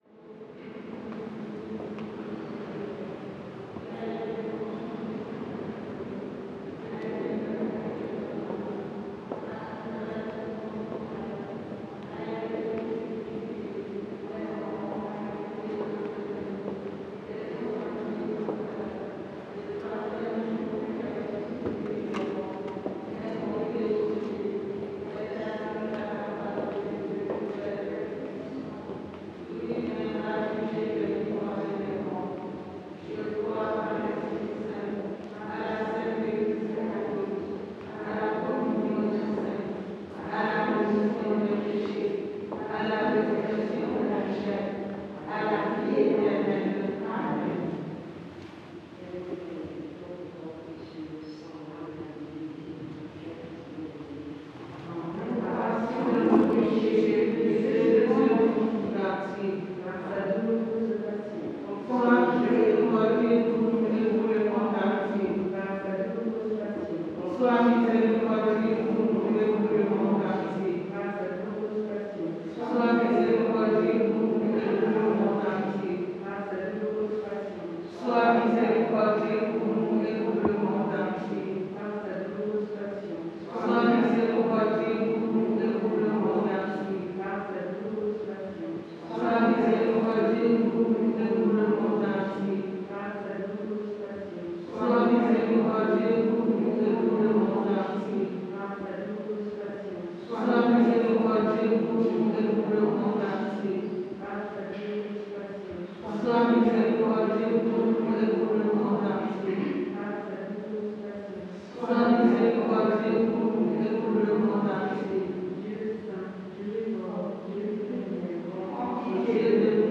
Prise de son dans l'église Saint-Jean-Baptiste de Belleville à 17 heures, situation à gauche du déambulatoire, 139 Rue de Belleville, 75019 Paris, 12 Mai 2021
fr CAPTATION SONORE